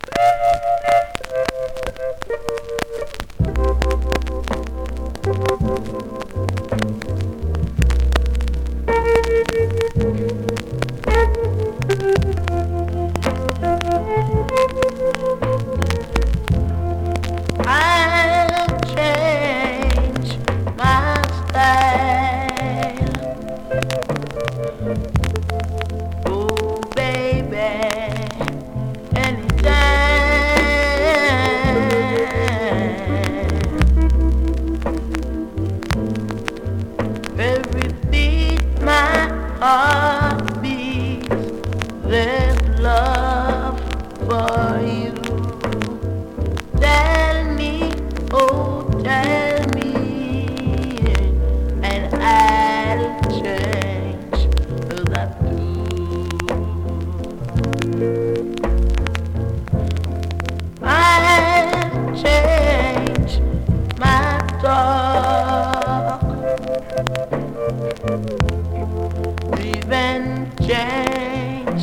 フチに少しHEAT DAMAGE、少し針がブレます)   コメントレアSKA!!YELLLOW VINYL!!
スリキズ、ノイズそこそこあります。